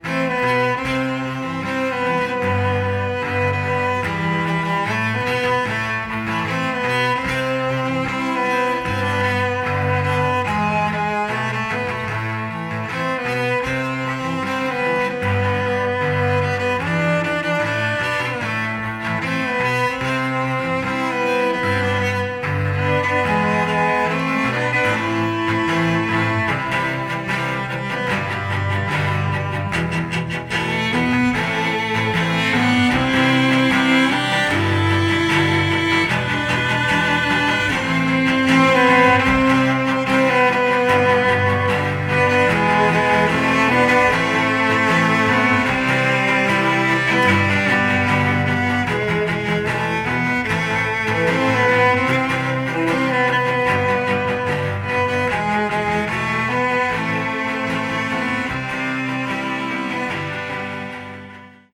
• Качество: 173, Stereo
спокойные
виолончель
Cellos